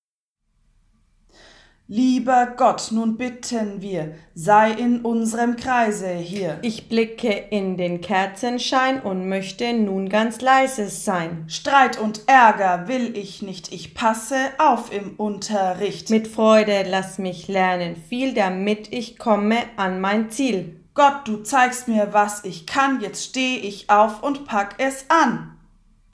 Rhythmische Texte und coole Beats